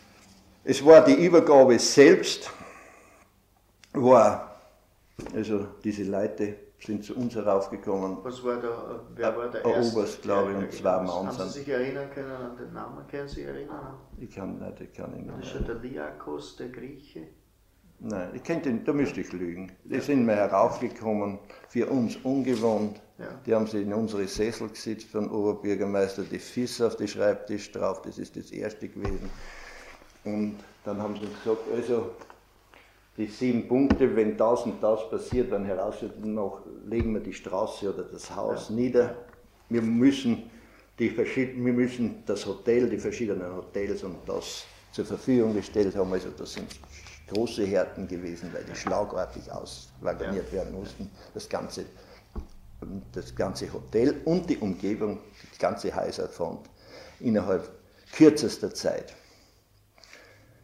Interview mit dem ehemaligen NS-Stadtrat Otto Zich über die Befreiung von Linz
Ausschnitt aus einem Interview mit dem NS-Stadtrat Otto Zich über den ersten Kontakt der NS-Stadtregierung mit den amerikanischen Truppen.